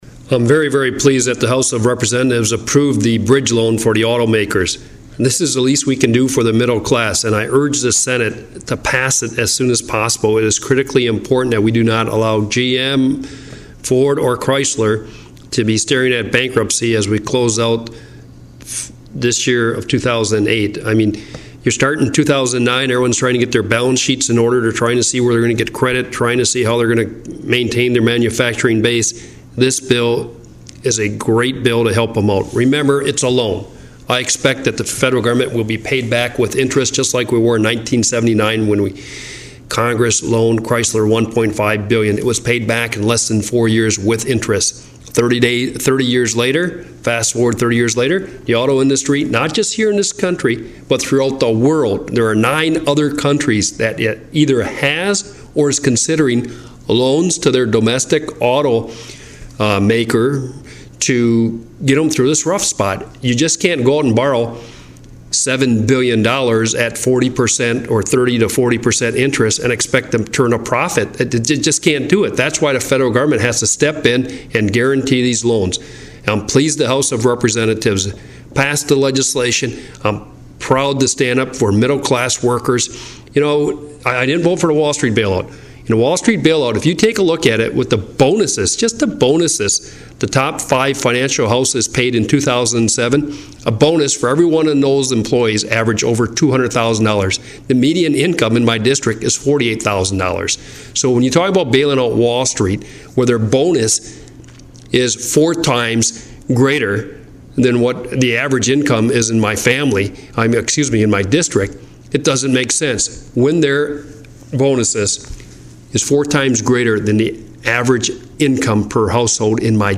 STUPAKComments from the Congressman about his vote for the Auto Industry Loan package that has passed in the US House of Representatives but is facing a tenuous future in the US Senate.